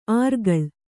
♪ ārgaḷ